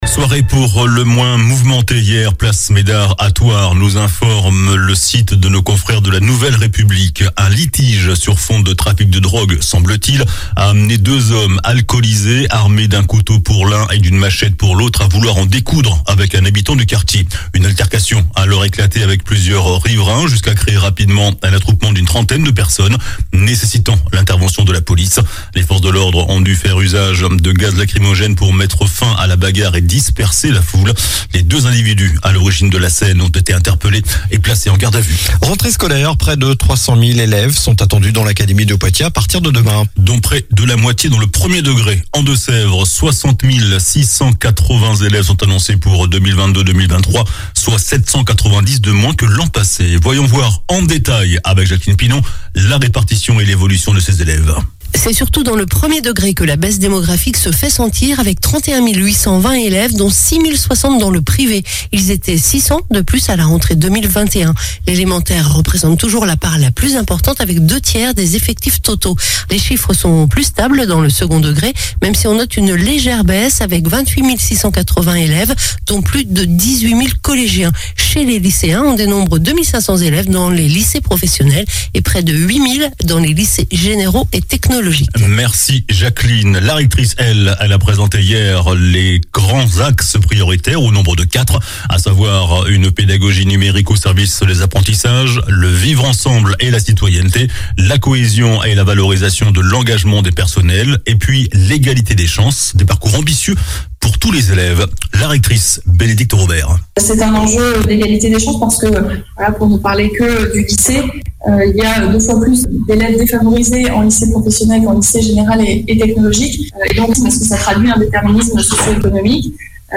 JOURNAL DU MERCREDI 31 AOÛT ( SOIR )